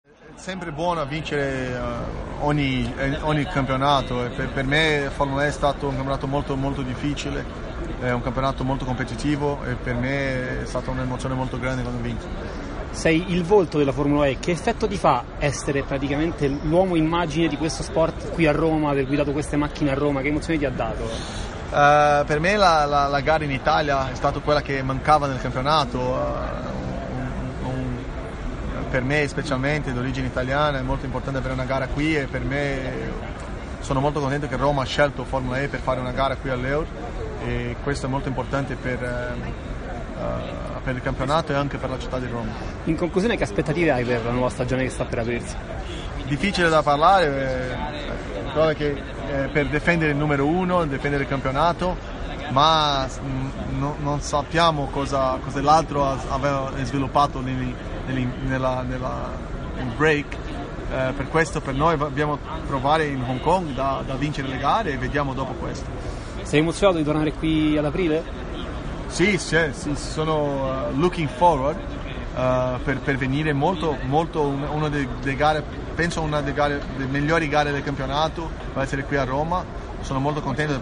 Ieri si è svolta a Roma la conferenza stampa di presentazione del primo Gran Premio di Formula E d’Italia, che si svolgerà nella capitale il 14 aprile 2018